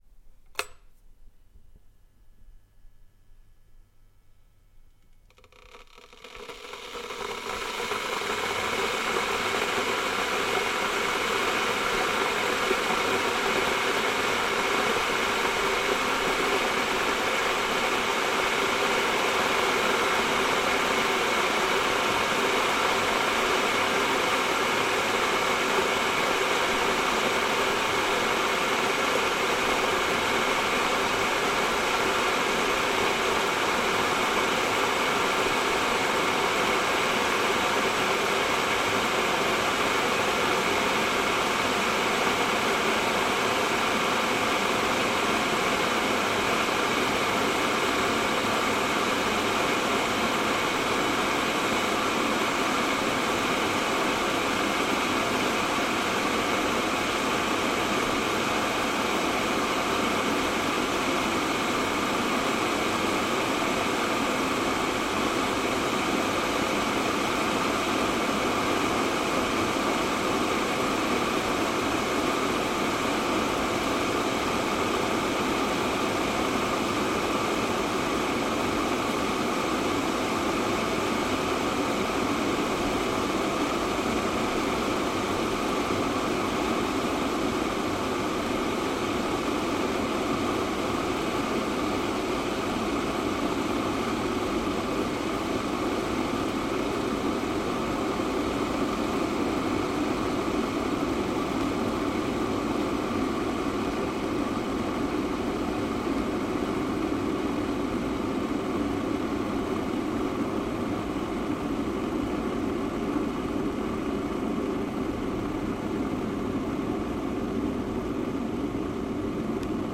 水壶沸腾
描述：打开一个非常古老的电水壶，同时使内部的水煮沸约2分钟。
Tag: 水壶 OWI 煮沸 酿造 咖啡